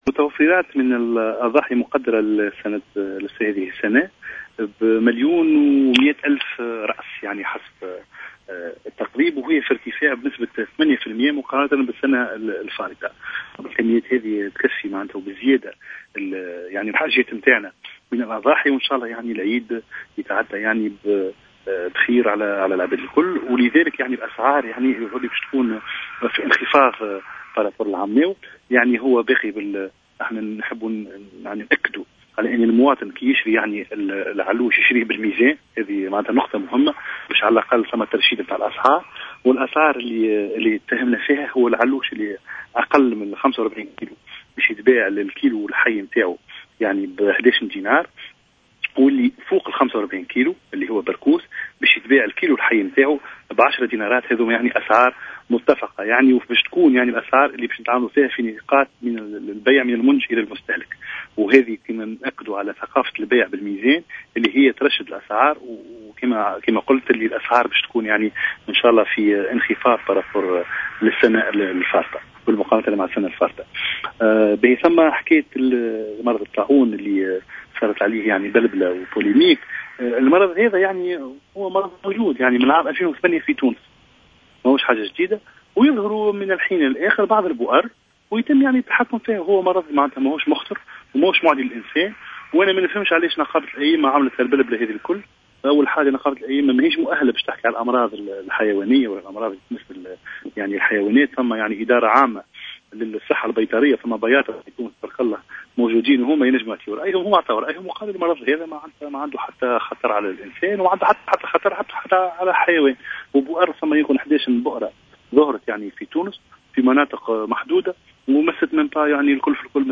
a déclaré ce samedi 20 août 2016 dans une intervention sur les ondes de Jawhara FM